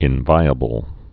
(ĭn-vīə-bəl)